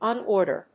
アクセントon órder